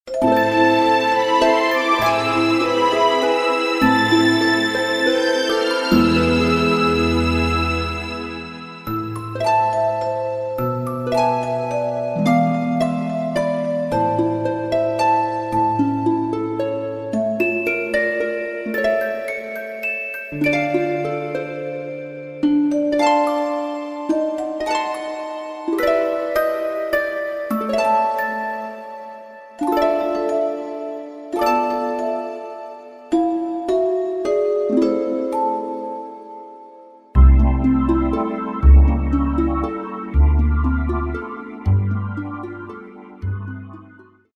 sans aucune voix